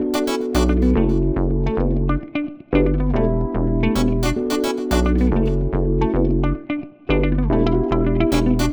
35 Backing PT1.wav